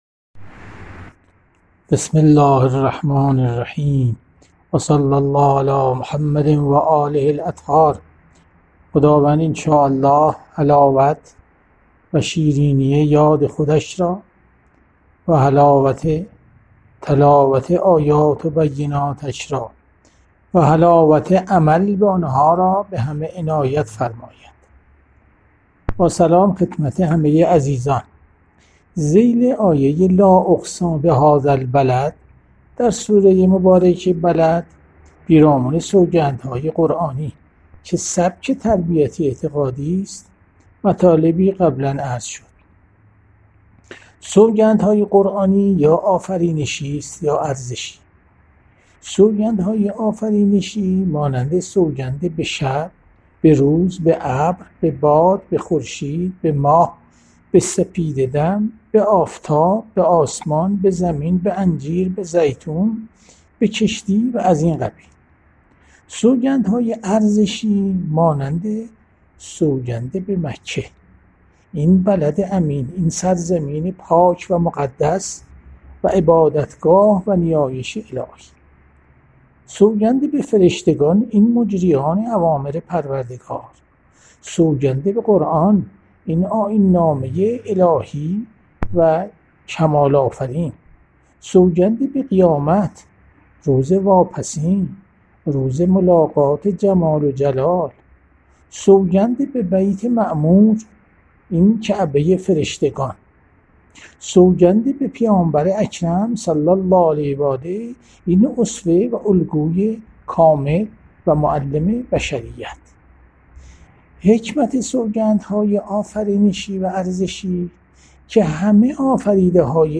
جلسه هفتگی تفسیر قرآن- سوره بلد- جلسه چهارم- 25 بهمن 1401